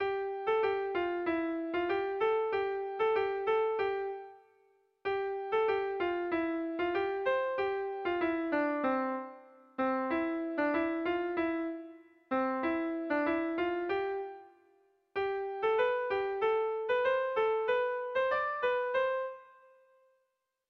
Dantzakoa
A1A2B1B2D